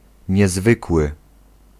Ääntäminen
IPA: [sɛ̃.ɡy.lje]